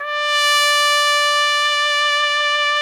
Index of /90_sSampleCDs/Roland L-CD702/VOL-2/BRS_Piccolo Tpt/BRS_Picc.Tp 2 St